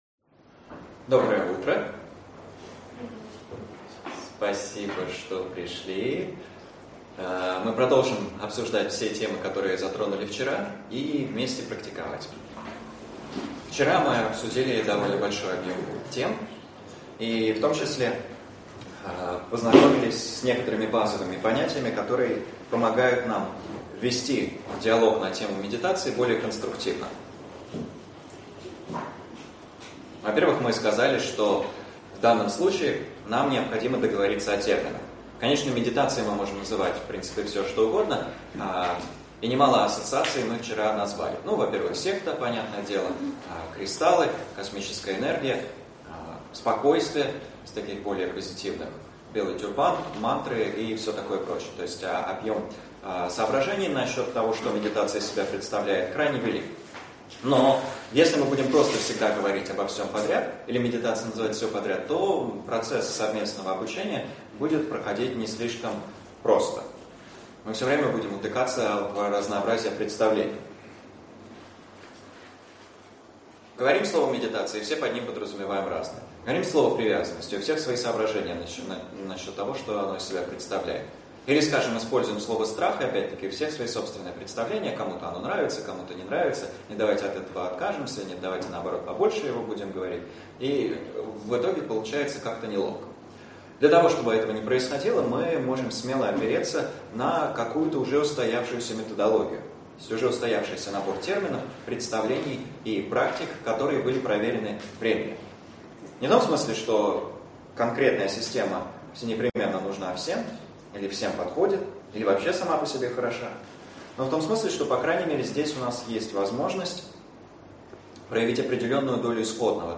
Аудиокнига Осознанность и дыхание. Часть 3 | Библиотека аудиокниг